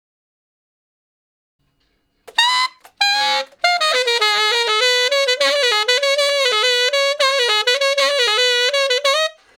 068 Ten Sax Straight (Ab) 22.wav